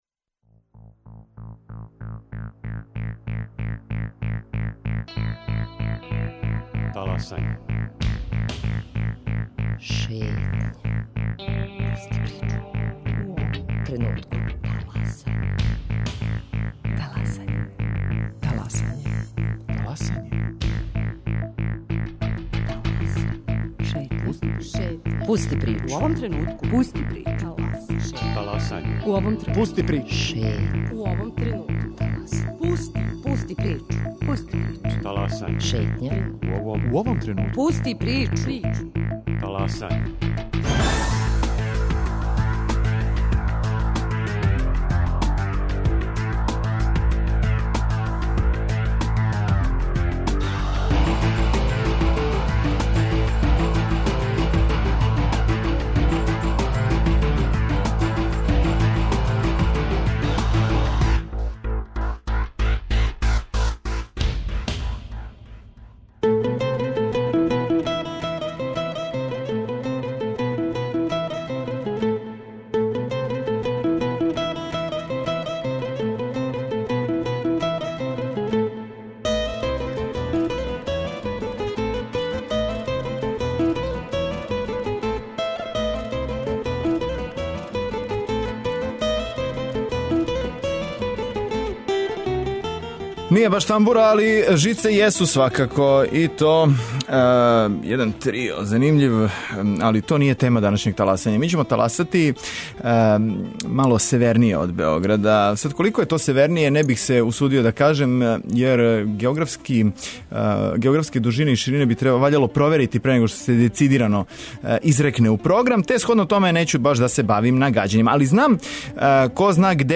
Радио Београд 1 је, у оквиру своје јесење турнеје по градовима Србије, у Бечеју одакле ћемо и реализовати нашу данашњу Шетњу по лепој бачкој варошици.